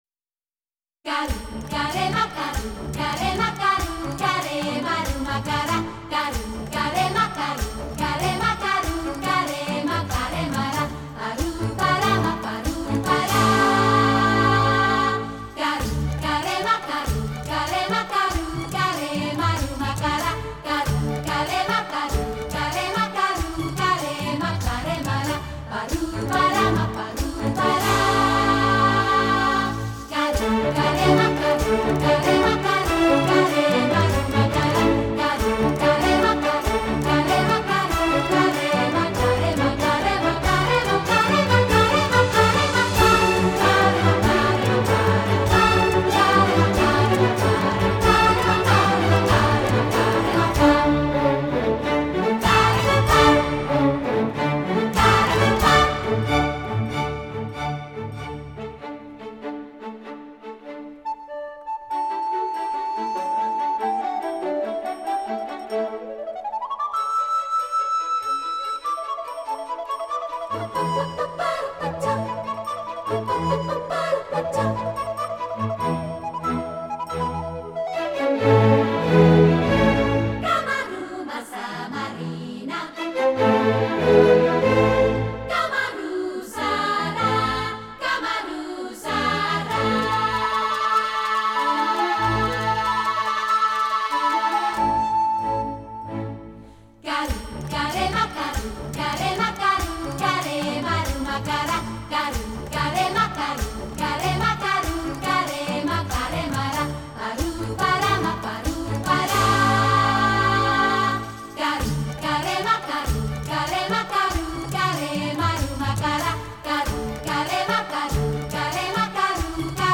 广告片背景音乐